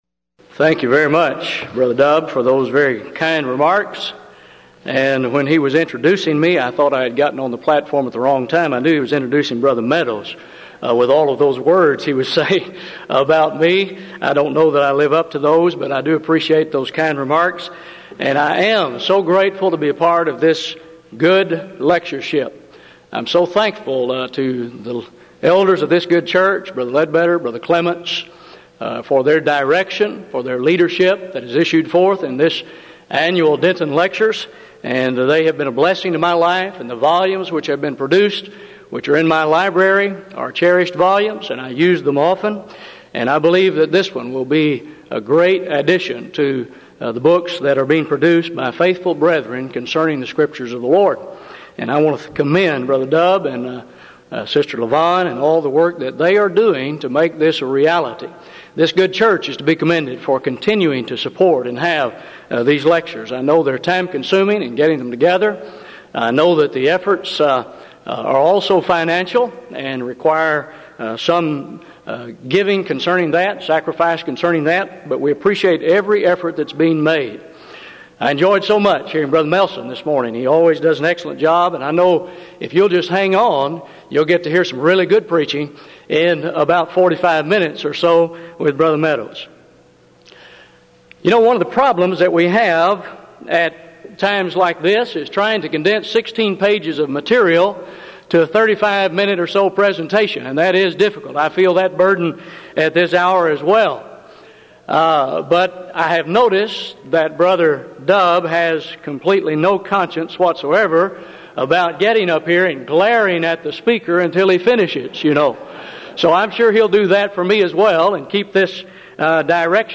Event: 1996 Denton Lectures
lecture